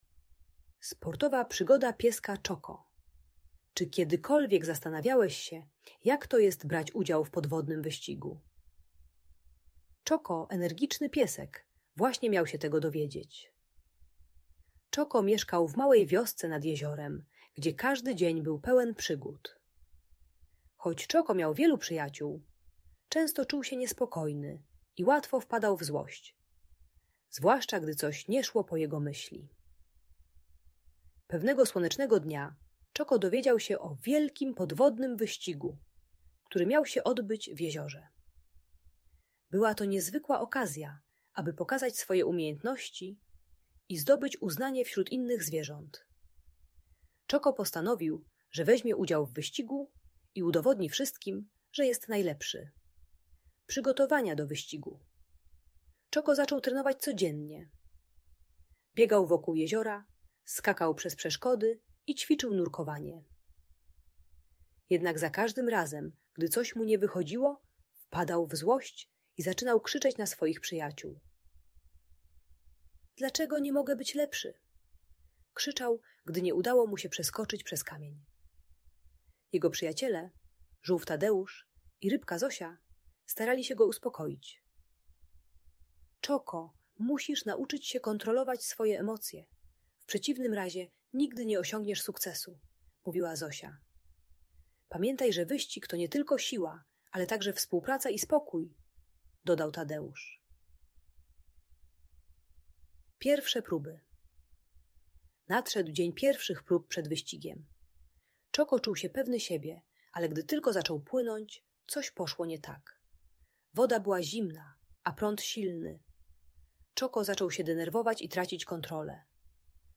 Sportowa przygoda pieska Czoko - inspirująca historia - Audiobajka